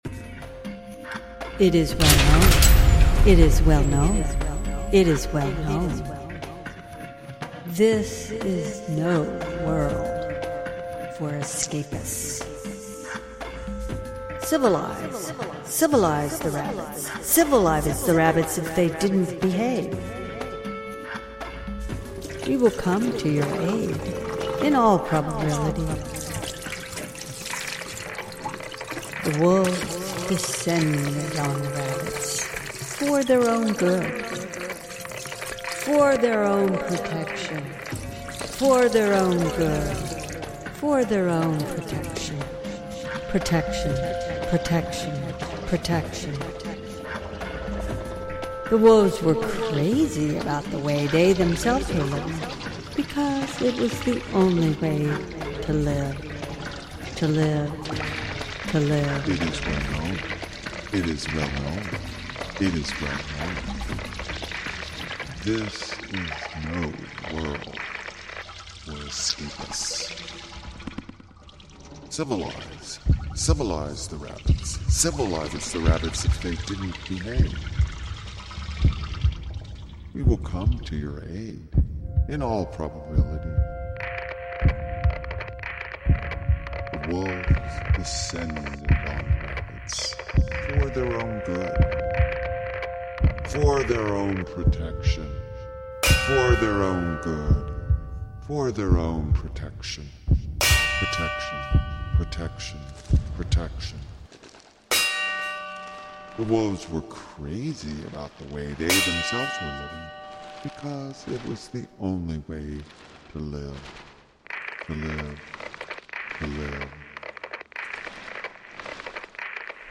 auditory imagining
The work is composed using voice, artist’s sound effects and digital files. The work compresses the story into a few phrases allowing the soundscape to convey the menacing emotional terrain.